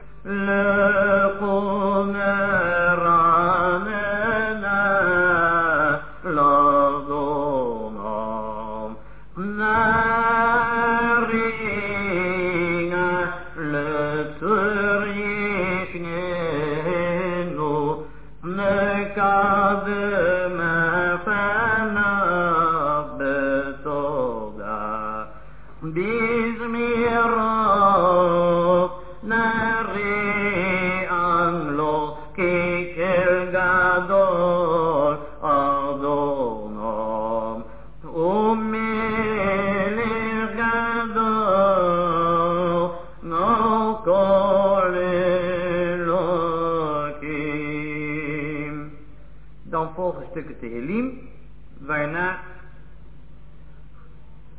This structure is used during a private Limud (Lezing) and on 15 Shevat.